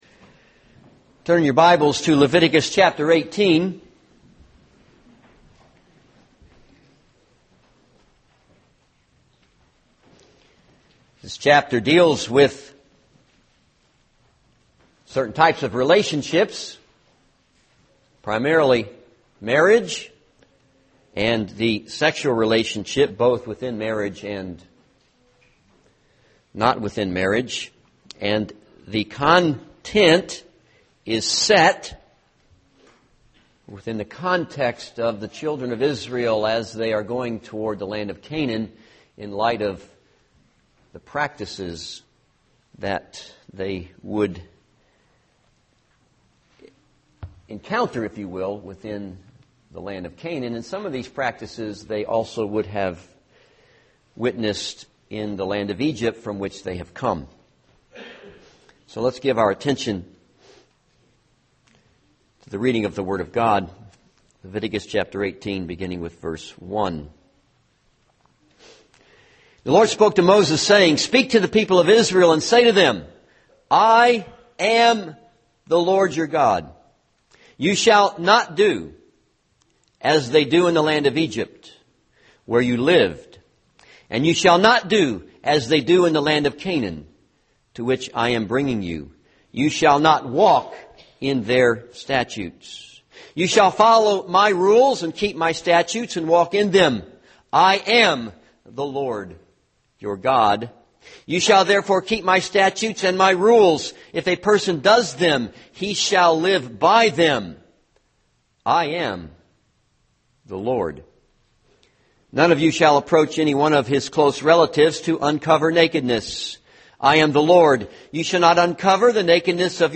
This is a sermon on Leviticus 18.